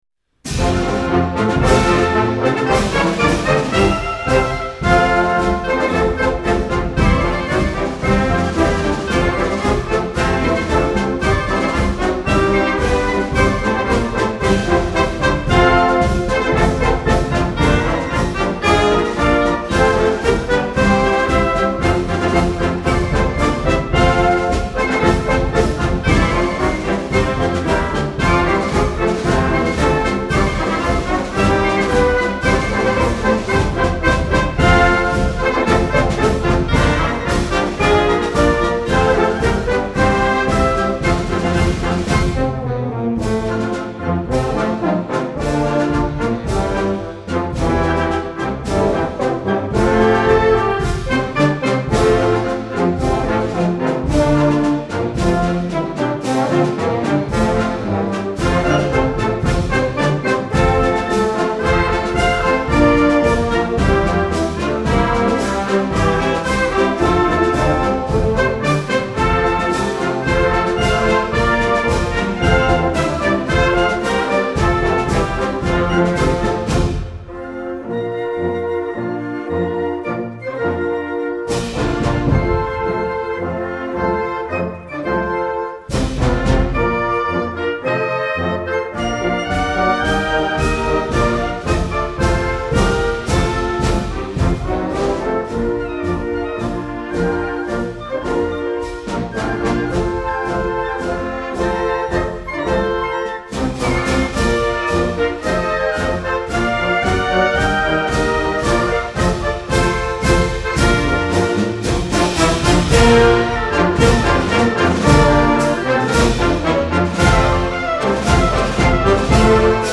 Marines Onguard March
Sounds of Sousa Band Video w/ Mini Score